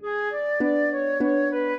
flute-harp
minuet4-4.wav